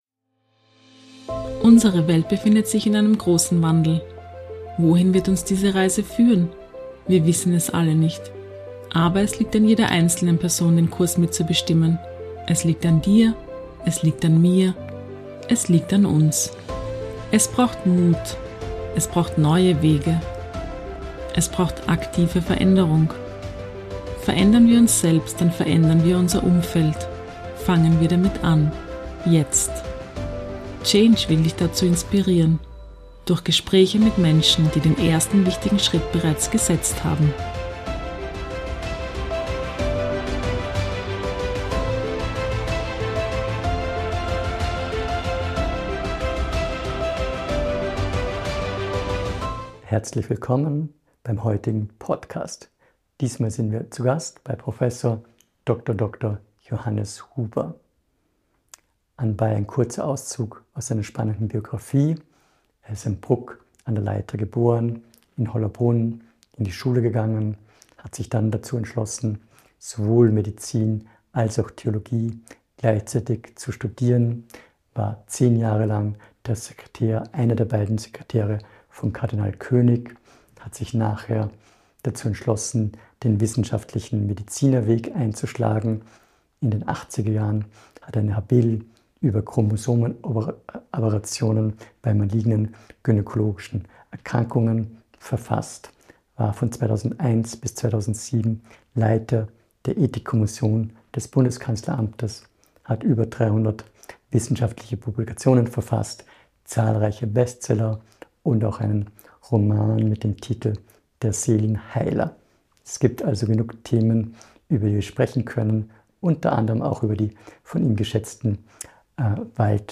Ein sehr inspirierendes und fundiertes Gespräch, das sich um Gesundheit, Altern, Medizin, Forschung und Ethik dreht.